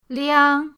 liang1.mp3